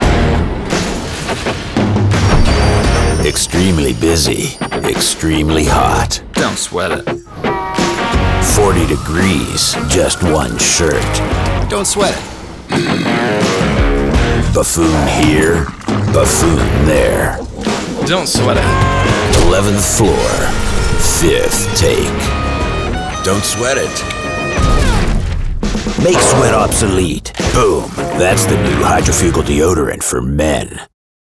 His specialties include commercial, narration, promo and corporate imaging.